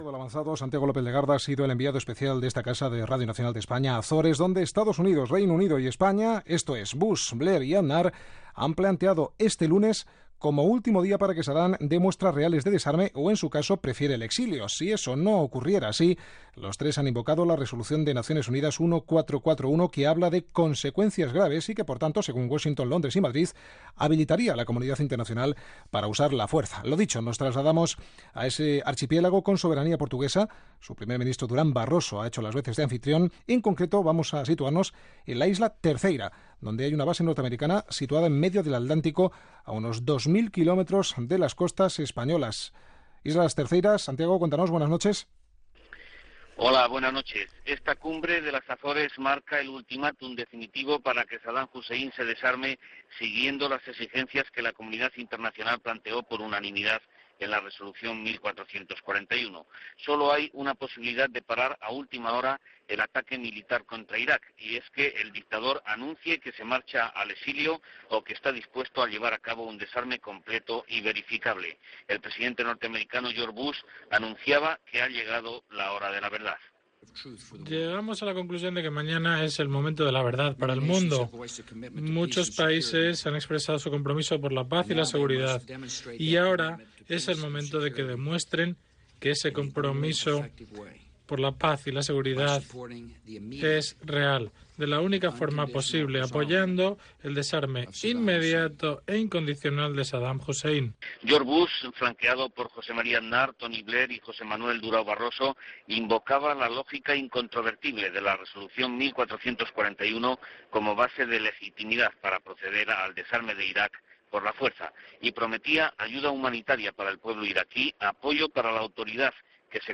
Informació de la cimera de les Açores feta pels presidents dels EE.UU. (George W. Bush), Regne Unit (Tony Blair) i Espanya (José María Aznar) feta el 15 de març de 2003 on es va afirmar l'existència d'armes de destrucció massiva a Iraq (que posteriorment va resultar falsa) i s'amenaçava a Sadam Husein de declarar la guerra. Connexió amb Bagdad.
Informatiu